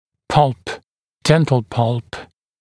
[pʌlp] [‘dentl pʌlp][палп] [‘дэнтл палп]пульпа (зуба)